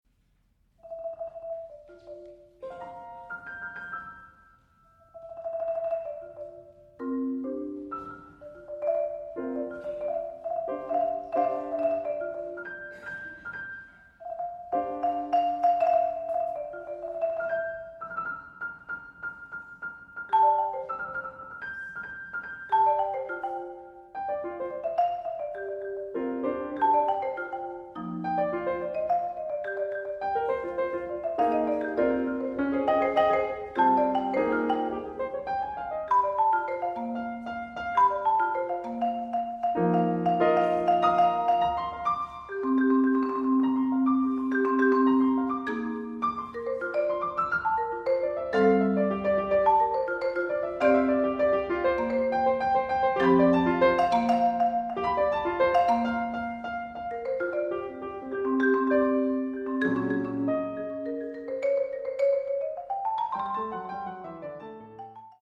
There is also a version for Marimba and Piano.